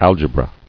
[al·ge·bra]